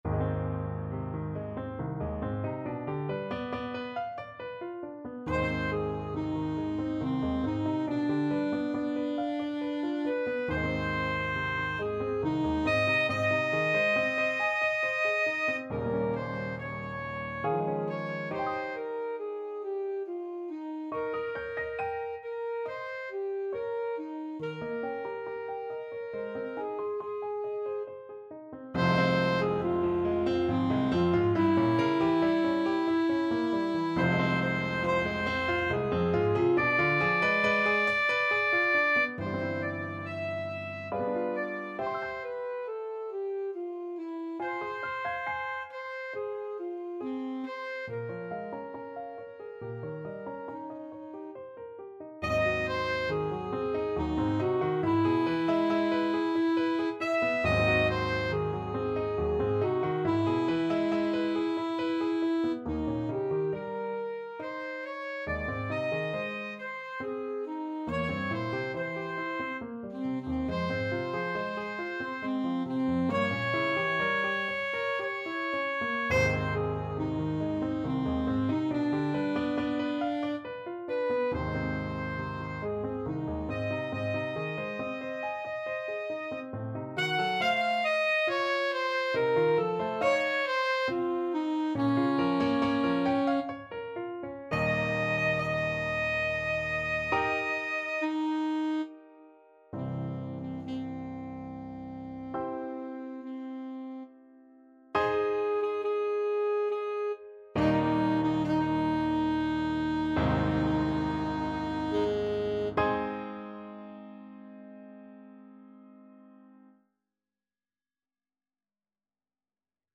Alto Saxophone version
Alto Saxophone
3/4 (View more 3/4 Music)
~ = 69 Large, soutenu
Ab4-F#6
Classical (View more Classical Saxophone Music)